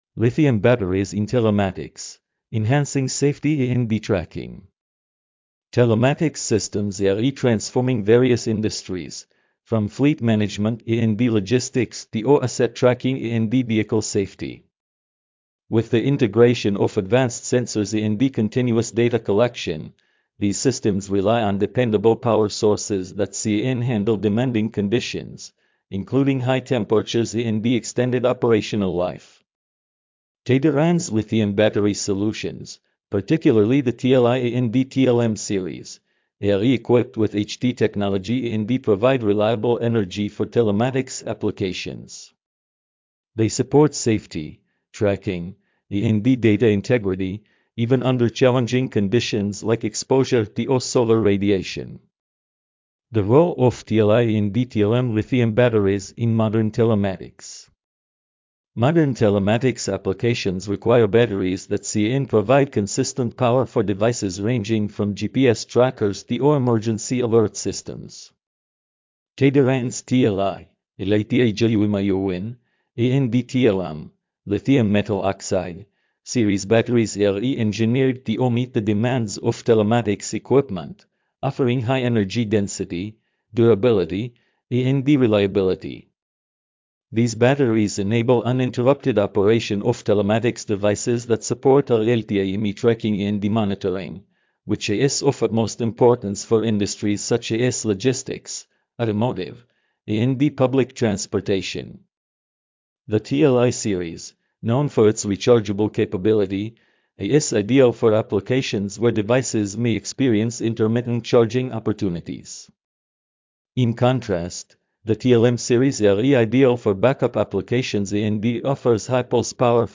Playing the article for the visually impaired: